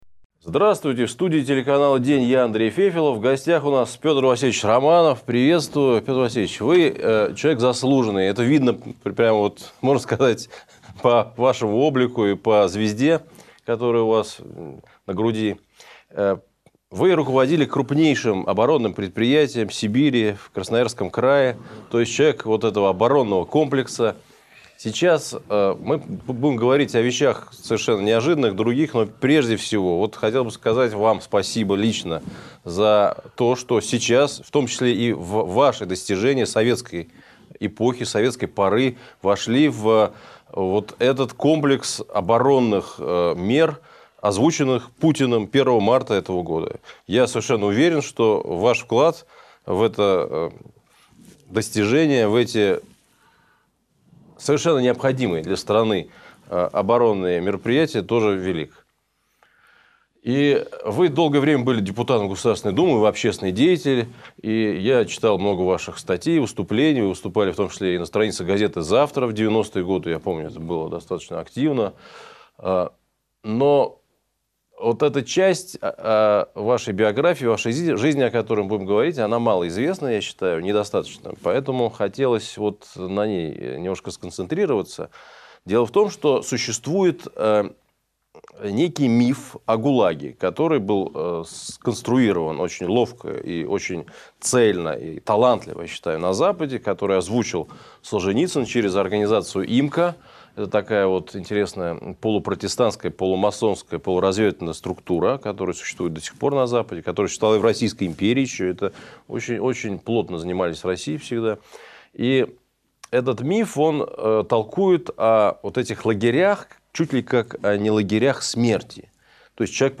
Политик, герой социалистического труда Пётр Романов рассказывает о жизни в советских лагерях. Чего добивается сенатор Людмила Нарусова с новыми инициативами о десталинизации российского общества.